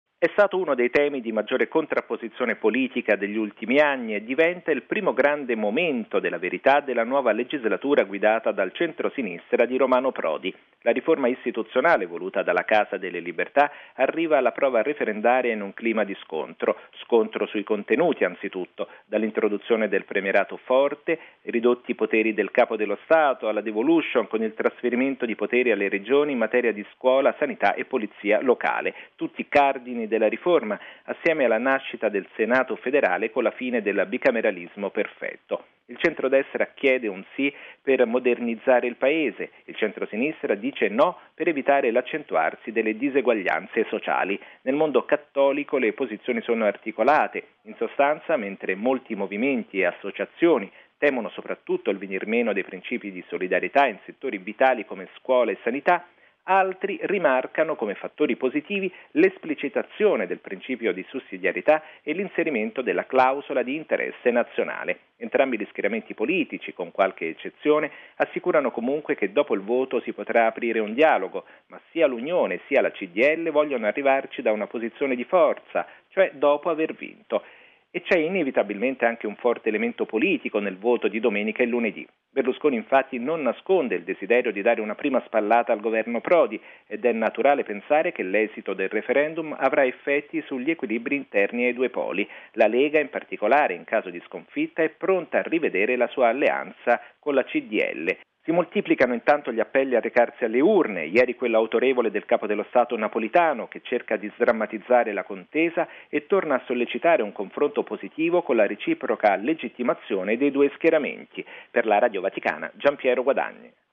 Le posizioni in campo e i temi del confronto nel servizio